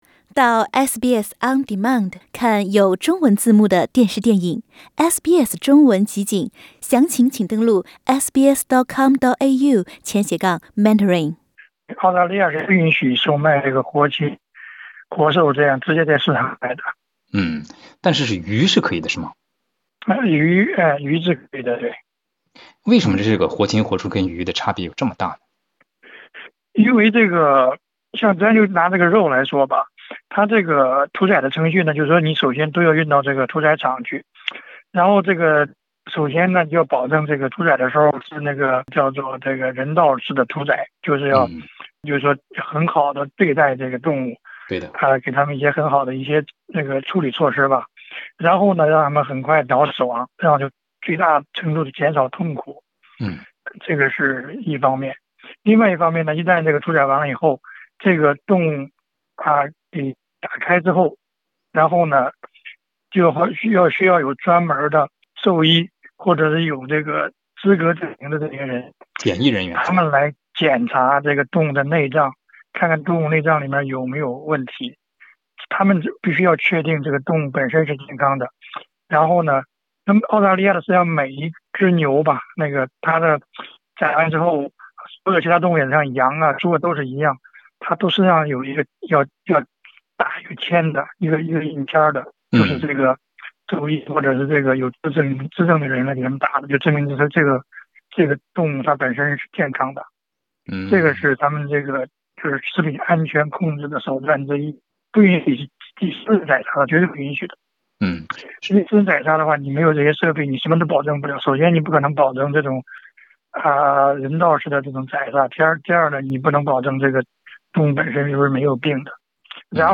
澳大利亚没有活禽活畜市场，但有活鱼市场，你知道这是为什么吗？点击上方图片收听采访录音。